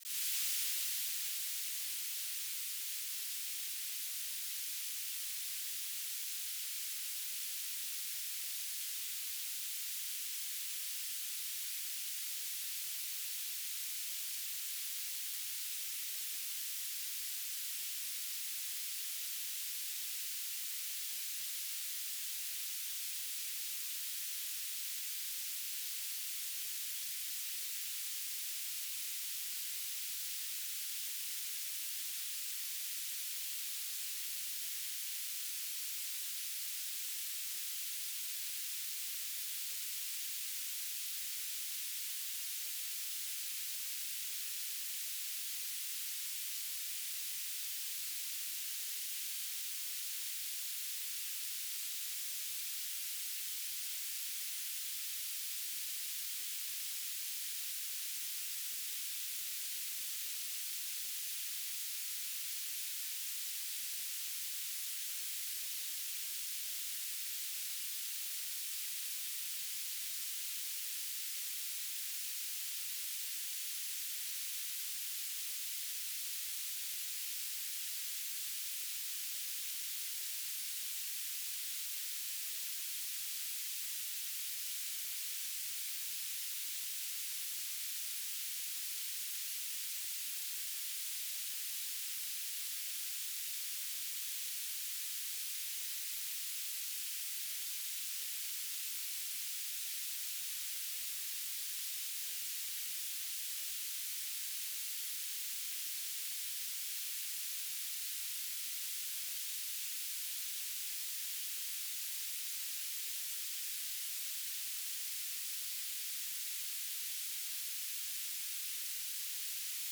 "transmitter_description": "S-band telemetry",
"transmitter_mode": "BPSK",